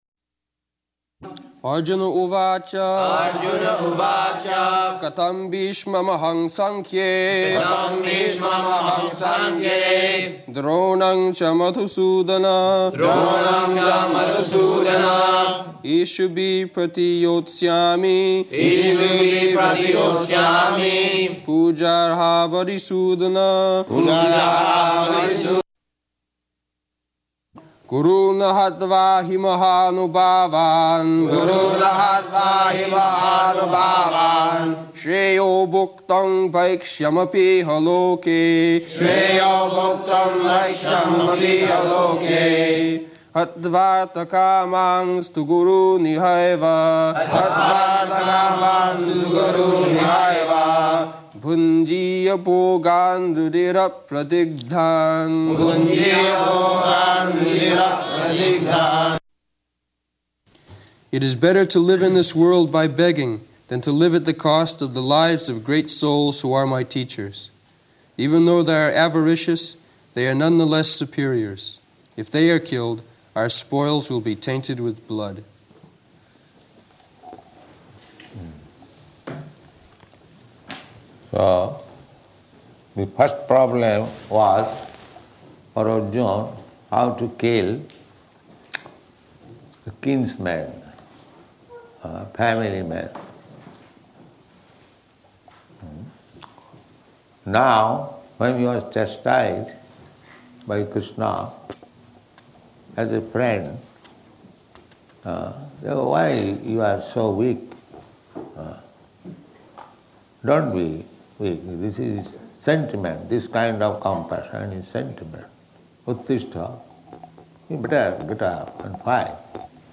Location: London
[leads chanting of verse] [Prabhupāda and devotees repeat]